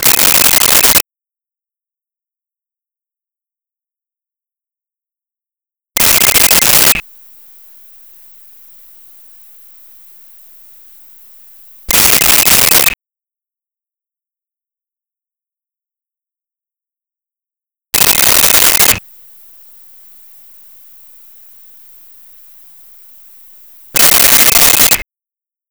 Cell Phone Ring
Cell Phone Ring.wav